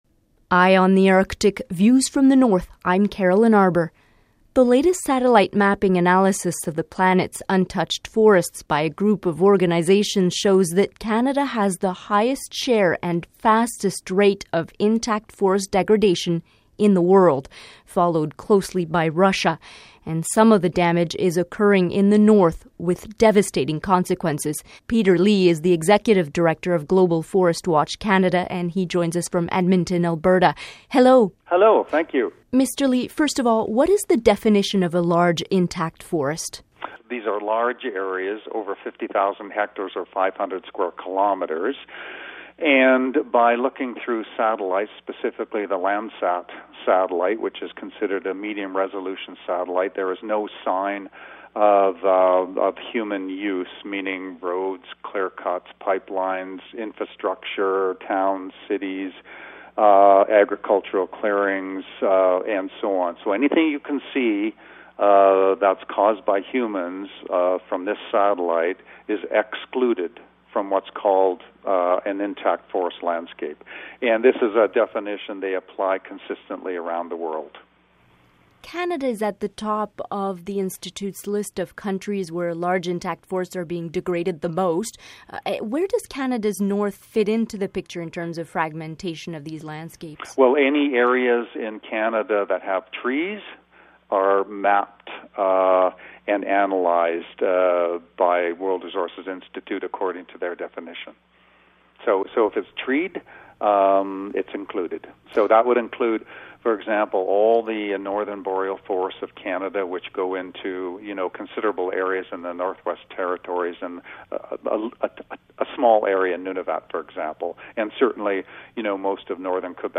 Feature interview: finger pointed at Canada, Russia, for degradation of intact boreal forest